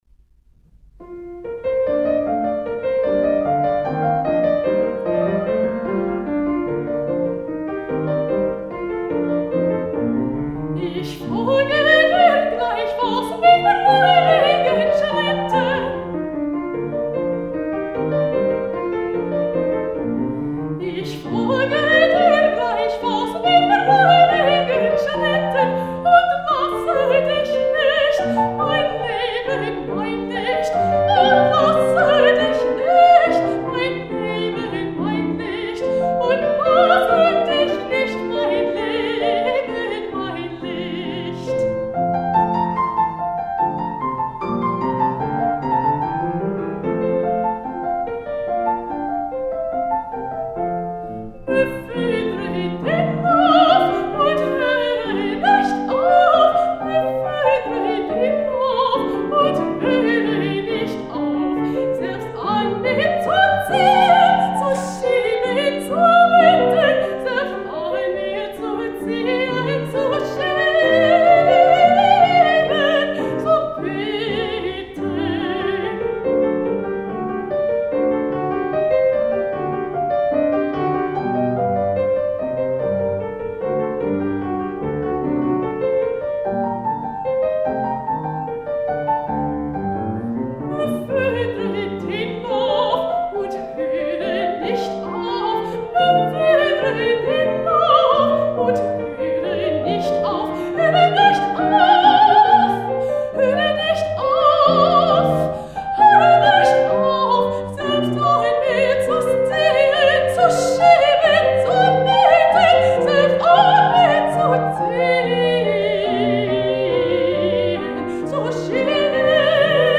Recorded at the San Francisco Conservatory of Music January 7, 2014